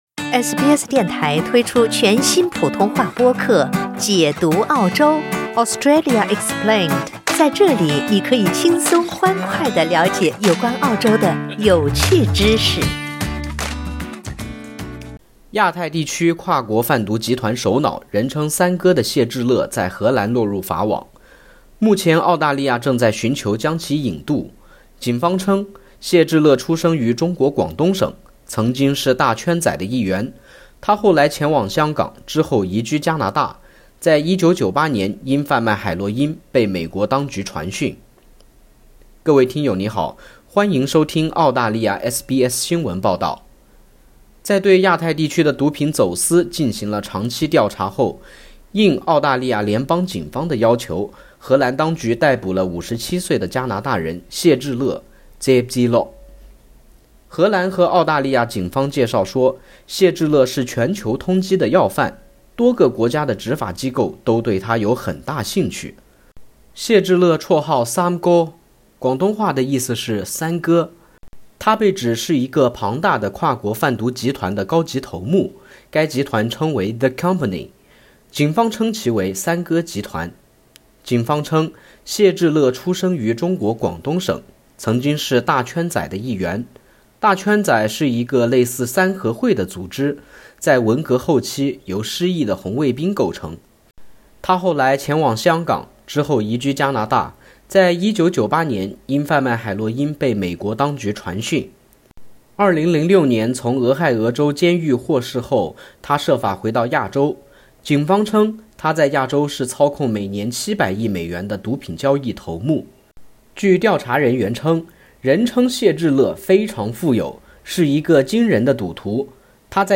亞太地區跨國販毒集糰首腦、人稱“三哥”的謝志樂在荷蘭落入法網，目前澳大利亞正在尋求將其引渡。(點擊上圖收聽報道)